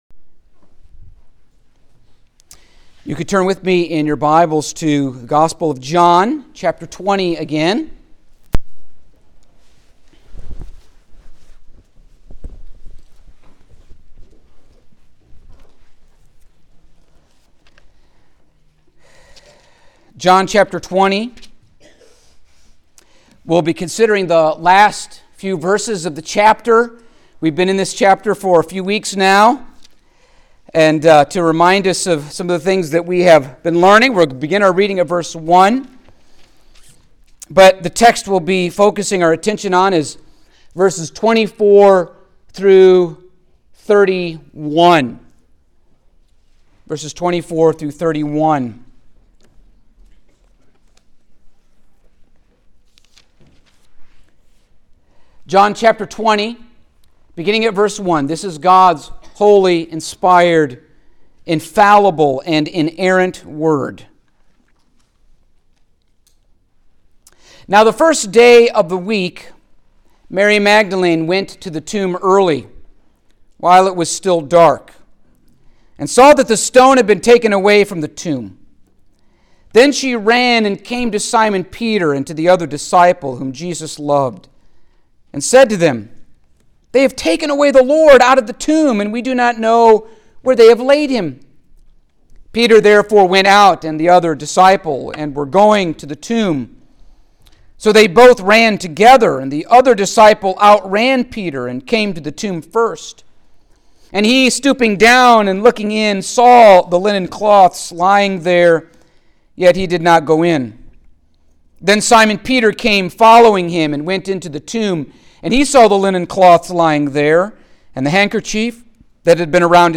Passage: John 20:24-31 Service Type: Sunday Morning